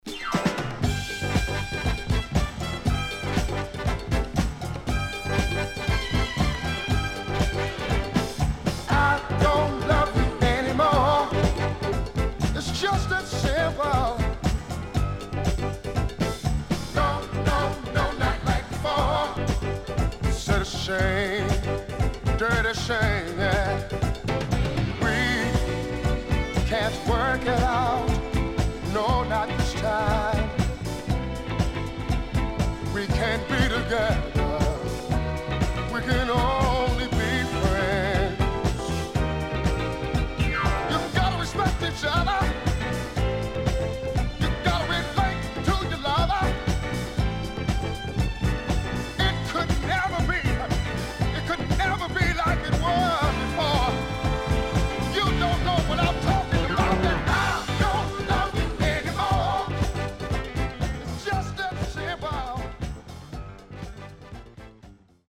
HOME > SOUL / OTHERS
CONDITION SIDE A:VG（OK)
SIDE A:序盤チリノイズ入ります。